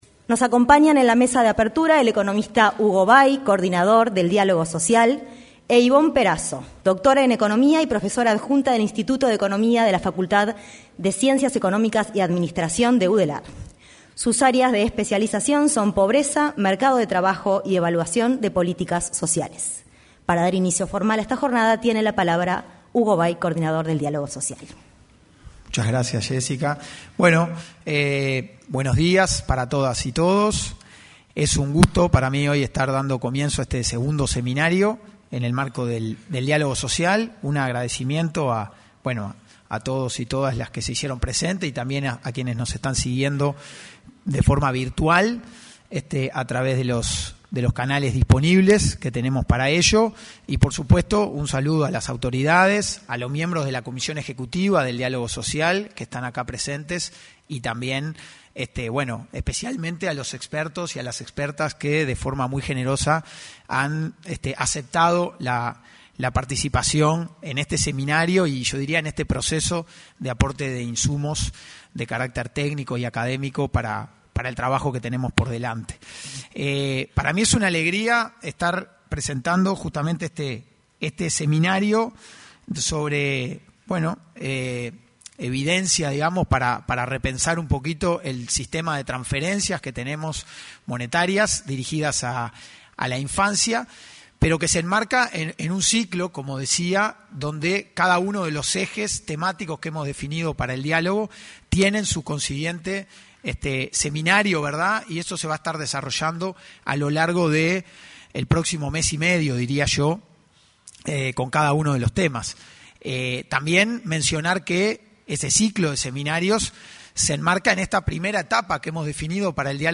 Se realizó el seminario Evidencia para Repensar las Transferencias Monetarias Dirigidas a la Infancia, en el auditorio del anexo a la Torre Ejecutiva.